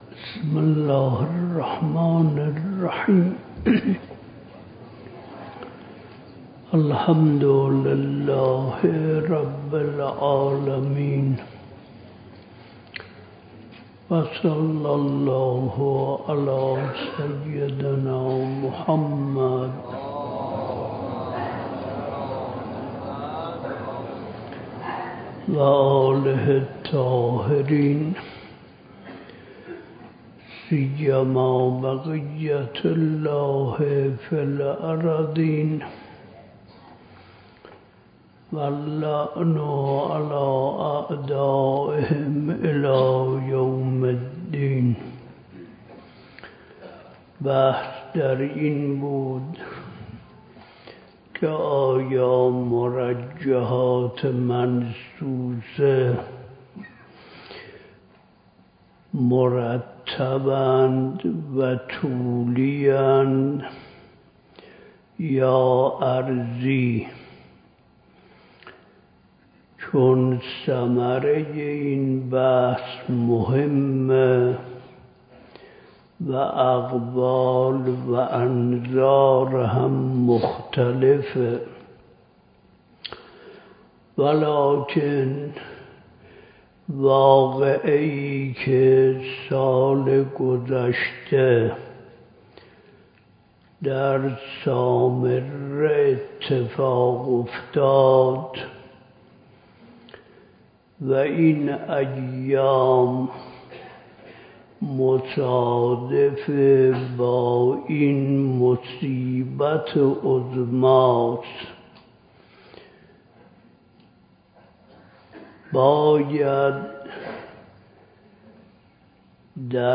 بیانات حضرت آیت الله العظمی وحید خراسانی مد ظله العالی با موضوع “تخريب سامرا” به صورت پخش آنلاین و دانلود فایل صوتی با لینک مستقیم.